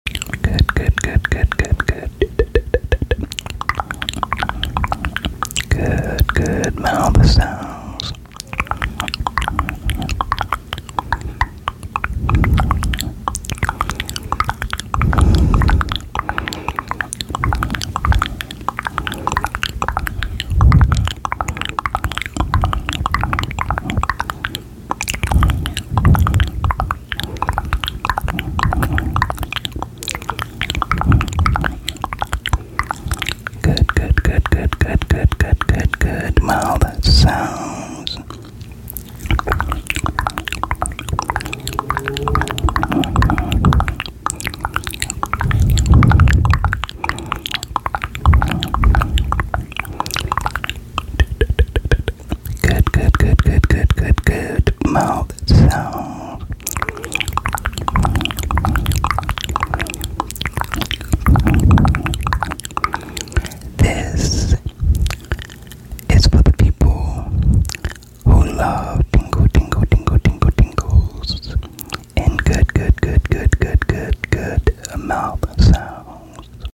ASMR #1 MOUTH SOUNDS IN sound effects free download
ASMR #1 MOUTH SOUNDS IN THE WORLD.